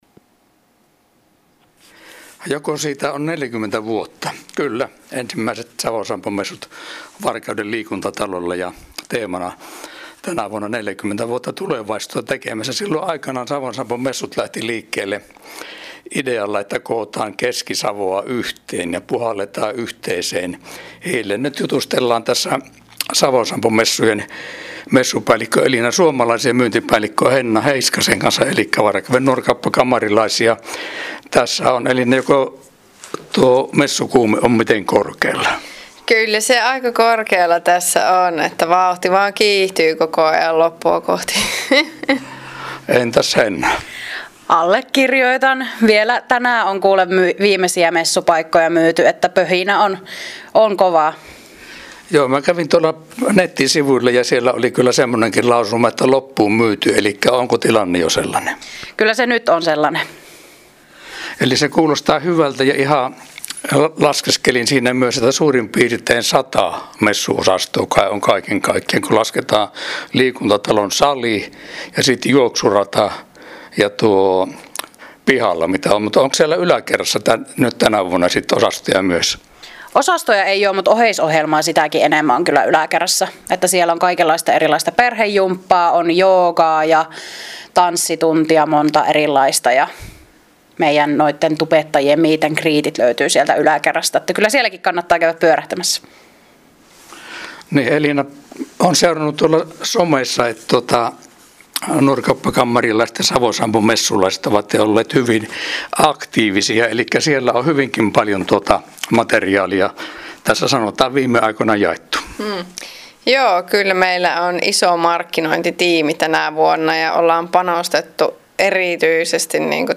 Äänihaastattelussa